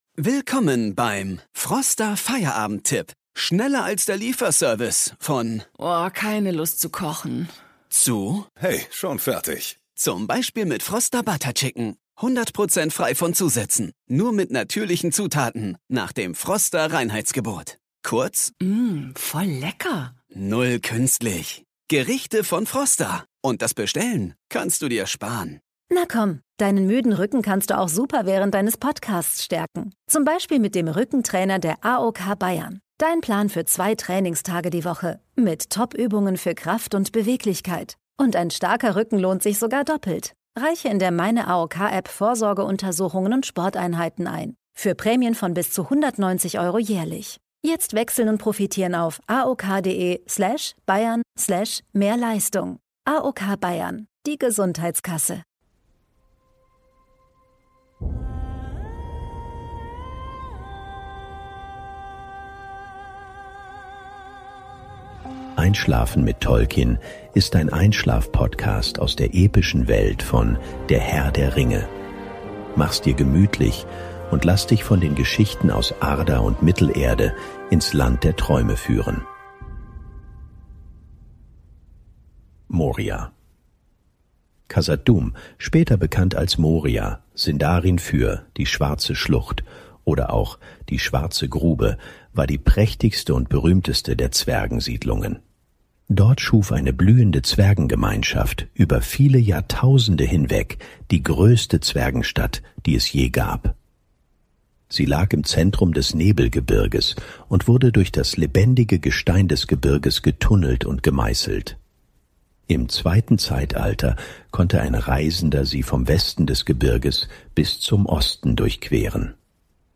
Audio Drama Schønlein Media Tolkiens Herr der Ringe Lord Of The Rings Gollum Ringe Der Macht Mittelerde Fantasy